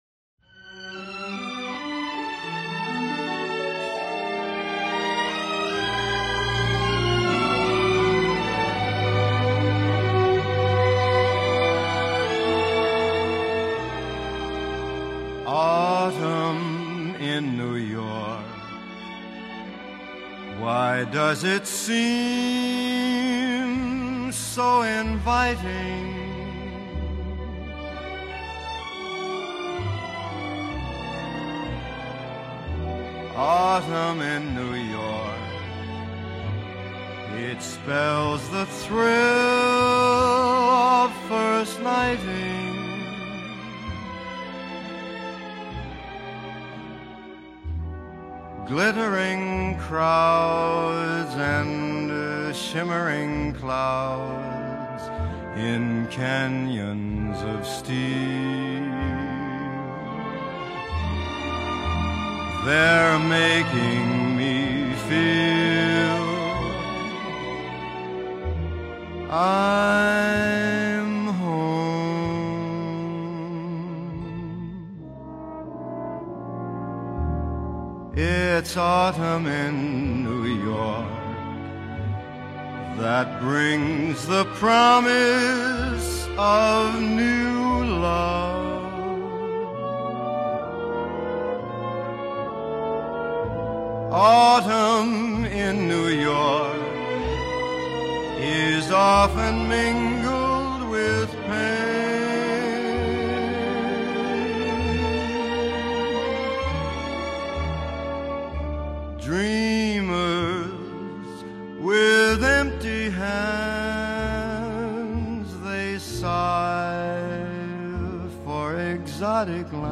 Jazz Vocal, Swing, Traditional Pop